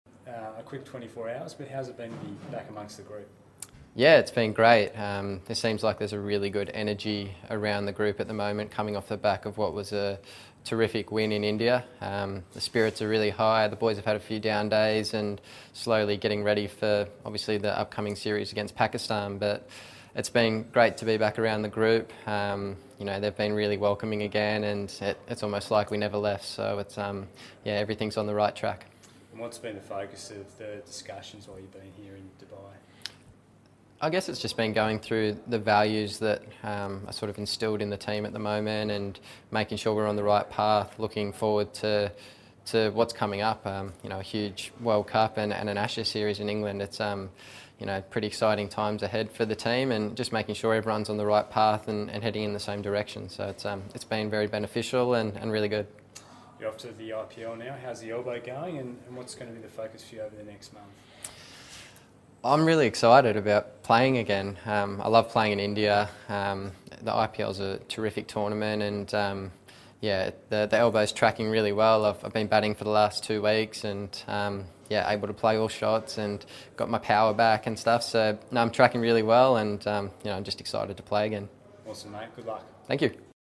Steve Smith Audio -Speaks ahead of the Qantas Tour of the UAE